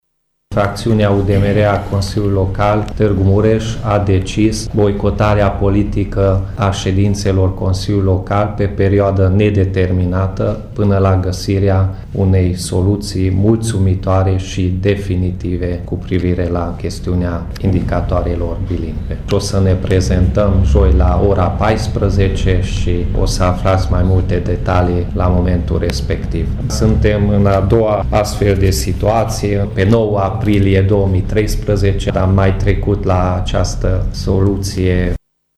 Asa a anunțat astăzi, într-o conferință de presă, viceprimarul UDMR Peti Andras.